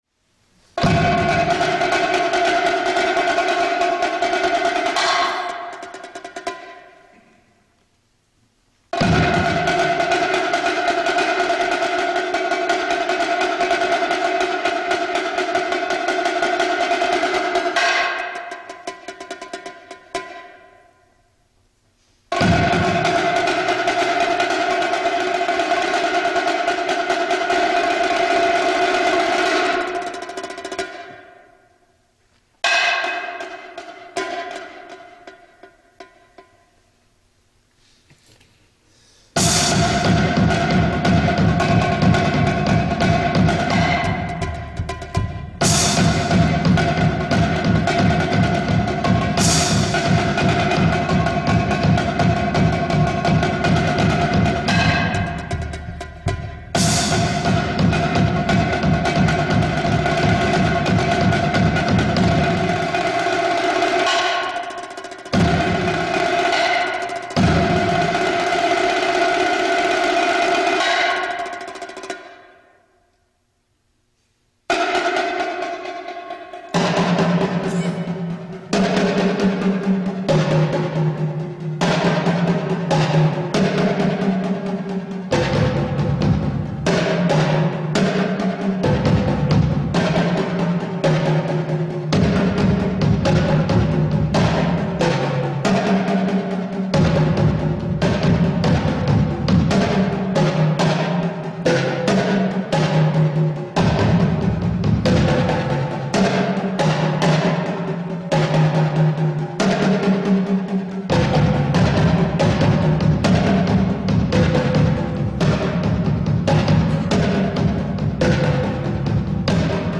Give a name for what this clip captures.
Voicing: Multi-Percussion Unaccompanied